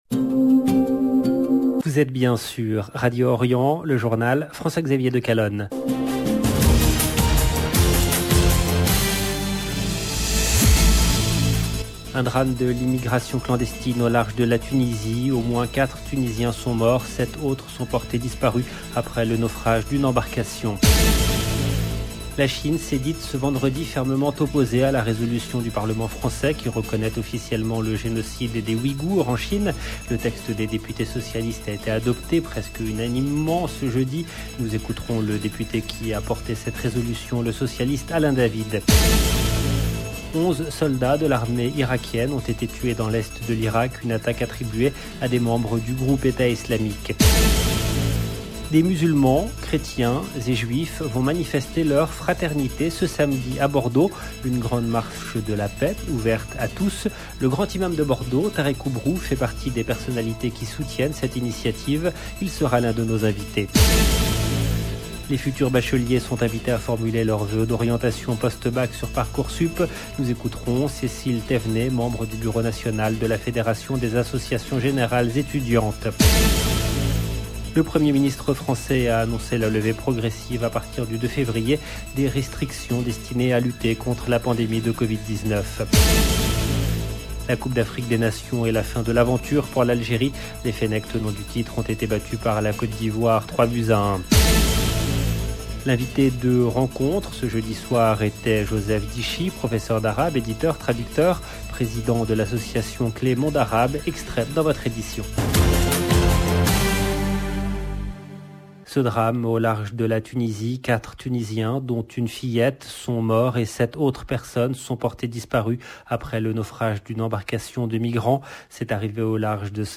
Nous écouterons le député qui a porté cette résolution, le socialiste Alain David.